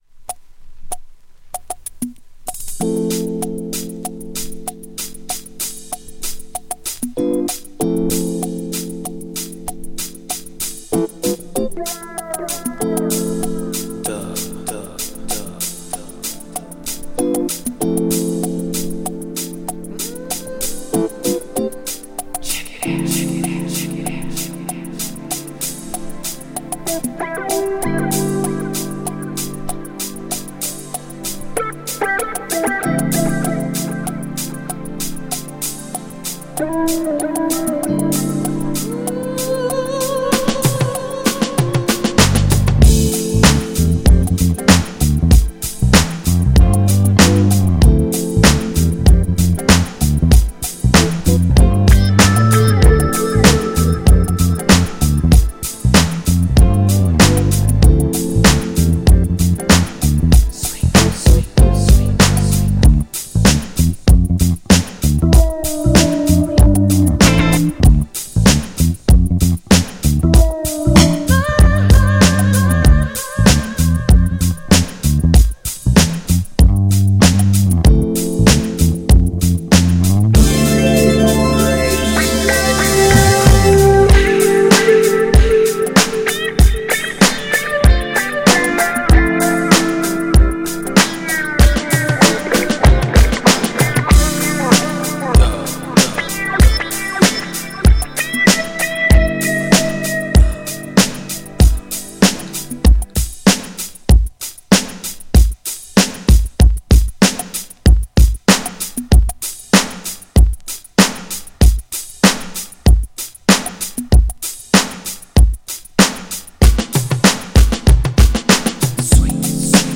GENRE R&B
BPM 101〜105BPM
# JAZZY